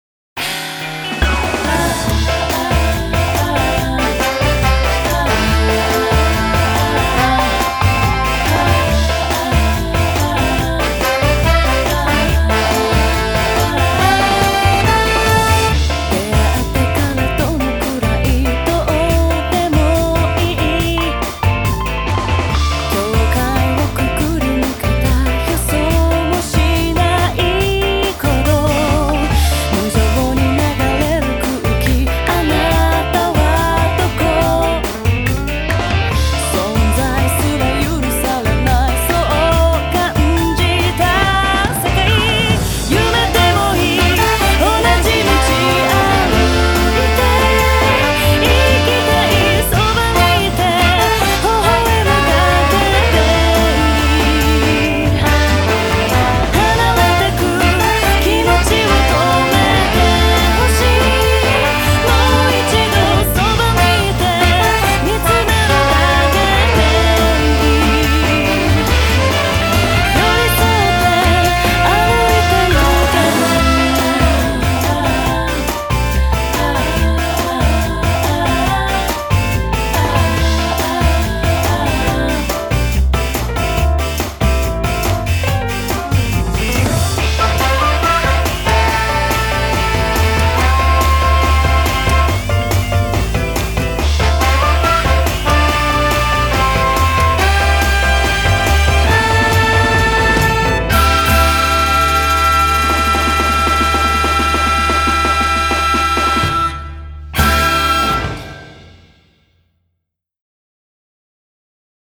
BPM74-141
Audio QualityPerfect (High Quality)
The end has a slowdown that was kinda hard to sync.